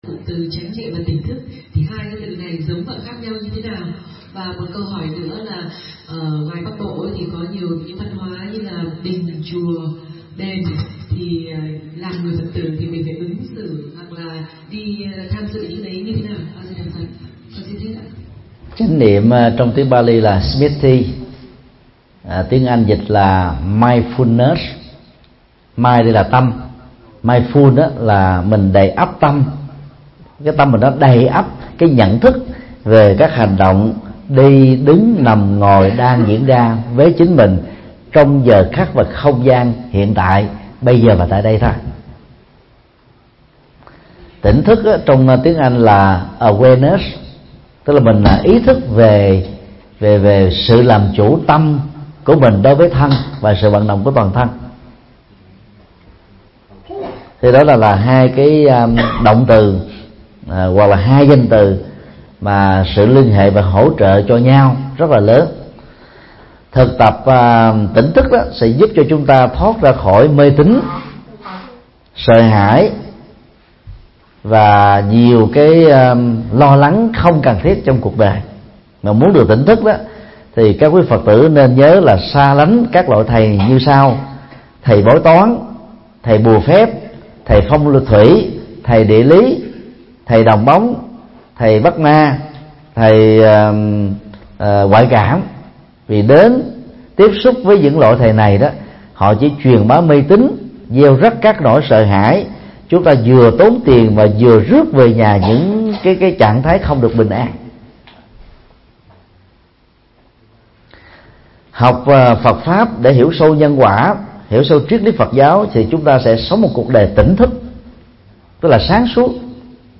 Vấn đáp: Phân biệt chánh niệm và tỉnh thức